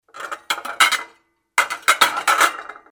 zvuk-tarelok_003.mp3